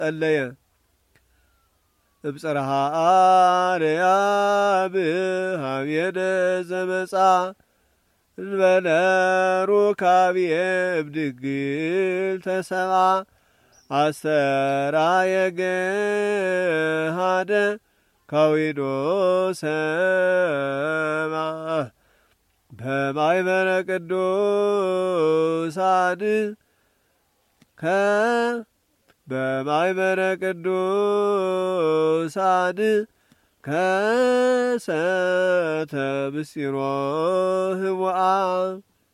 churchmusic/zema